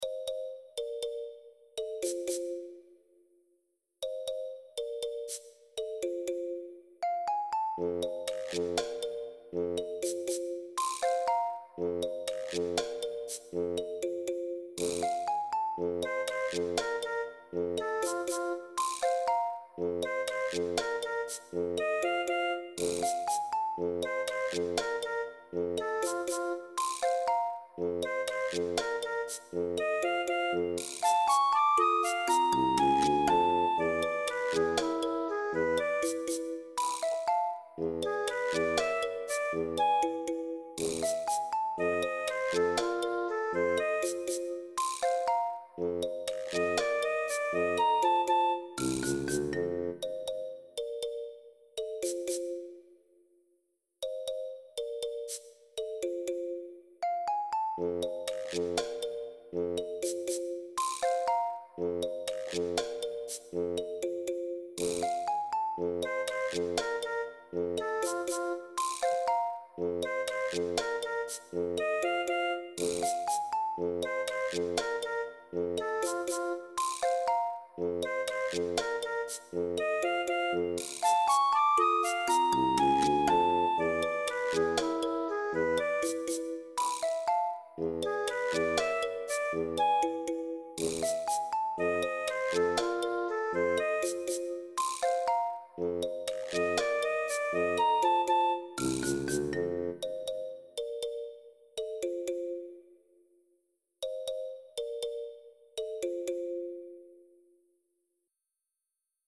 looping version